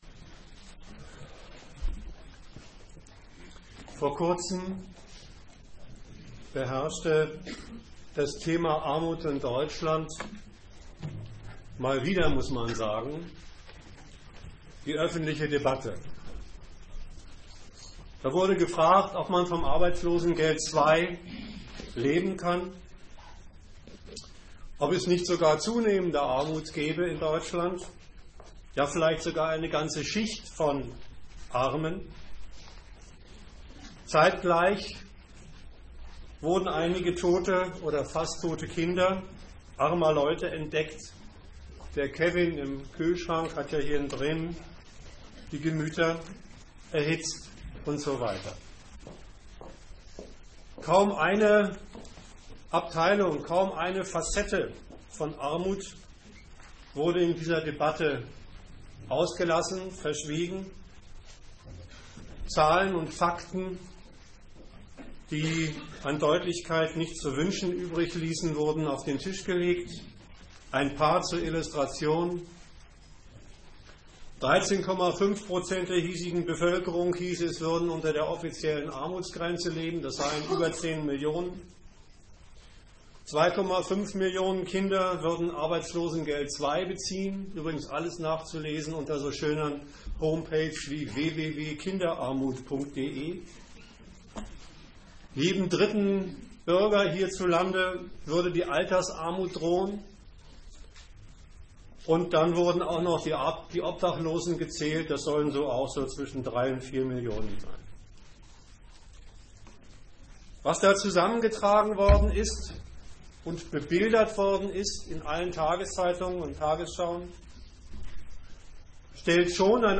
Gliederung des Vortrages: 1.